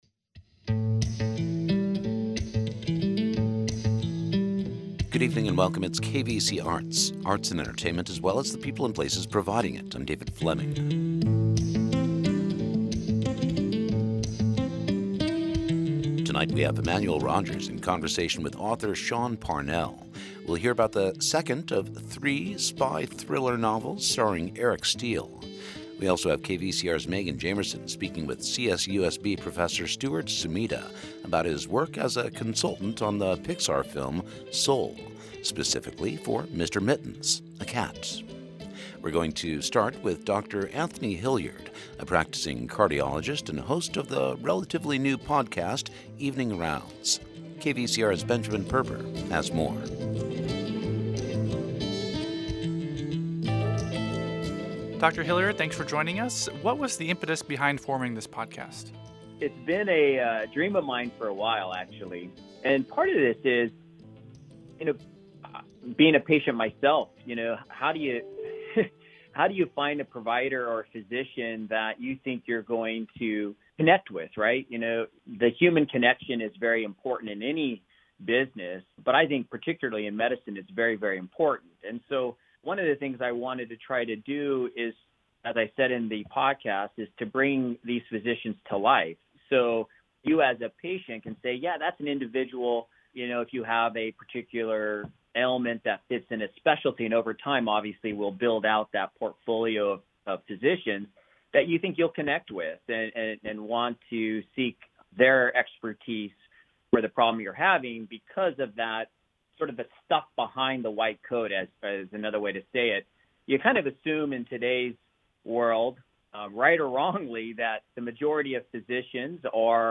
Interviews with authors, producers, visual artists and musicians, spotlighting a classic album or a brand new release.